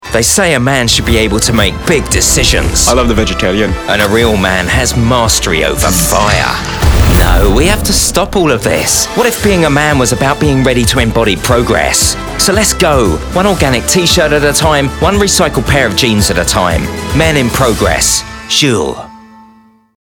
Anglais (britannique)
Promotions
Neumann TLM-103
Cabine insonorisée
Âge moyen
Baryton